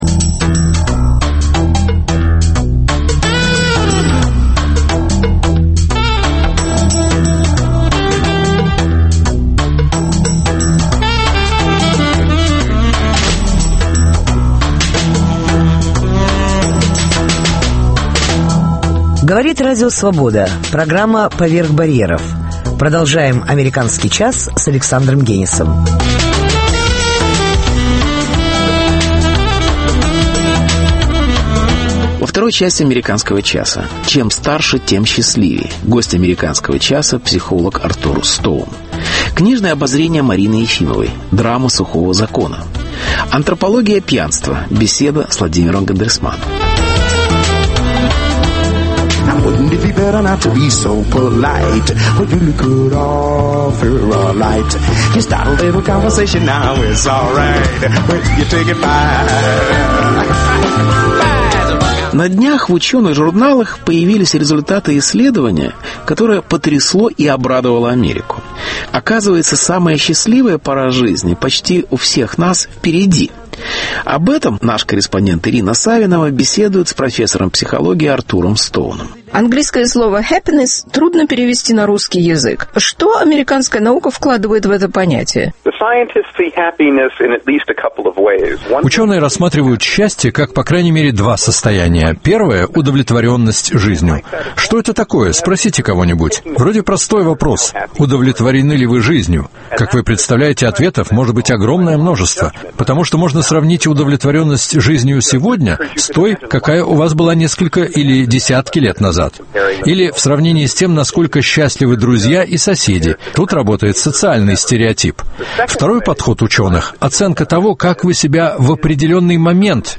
Чем старше, тем счастливее. Гость АЧ – психолог